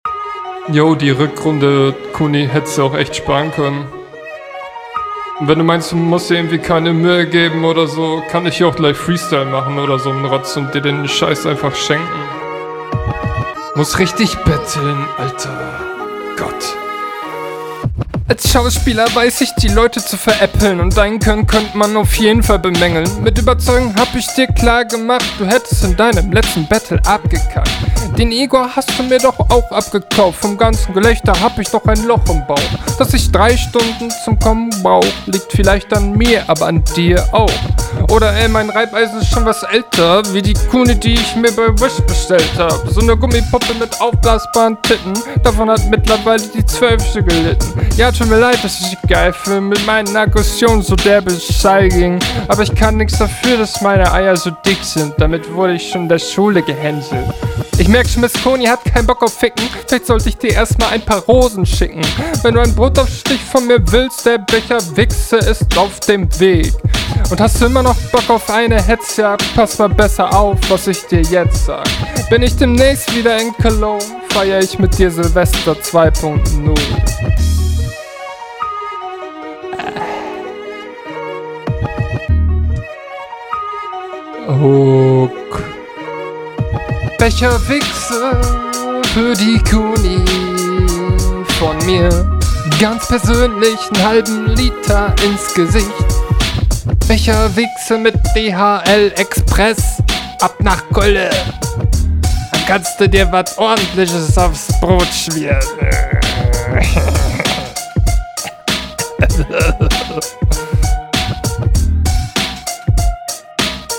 du verschluckst oft die wörter die hinrunde klingt alles in allem etwas flüssiger deshlab pkt …
Runde ist okay, hier hast du wenigstens Flow.